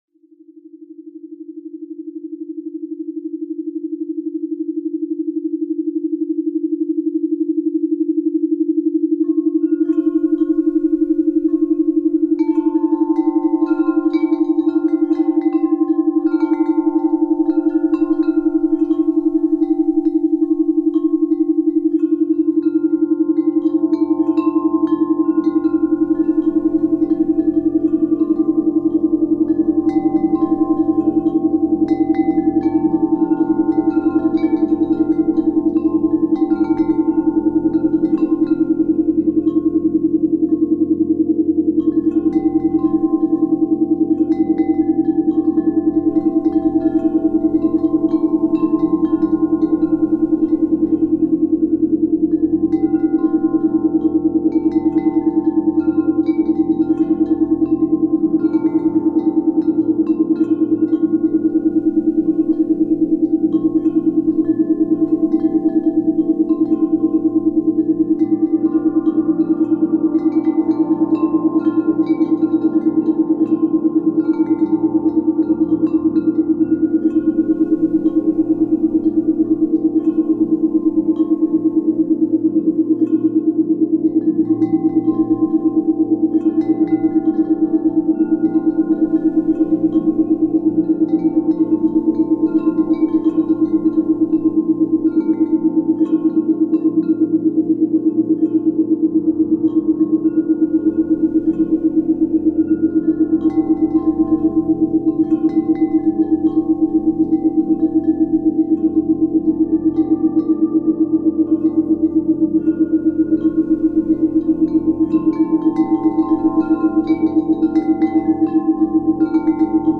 Очищение печени. В данной программе используются частота 317,83 Гц которая оказывает положительное воздействие на работу печени. Эта частота модулирована альфа и тета-ритмами для усиления ее эффективности.
Программа оказывает общее детоксифицирующее воздействие, стимулирует восстановление и очищение печени. Рекомендуется для прослушивания через стереонаушники.